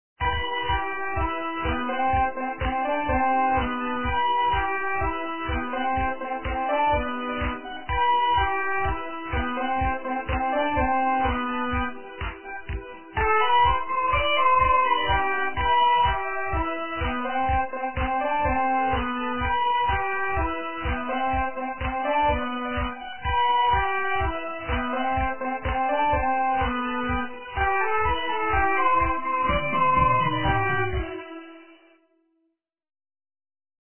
- украинская эстрада